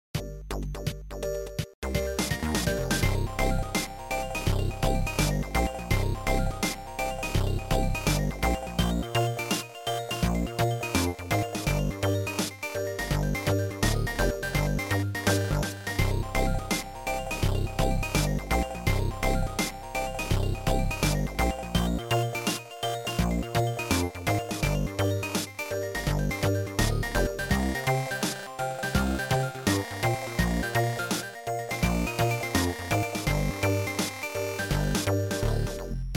Sound Format: Noisetracker/Protracker
Sound Style: Chip / Fun Tune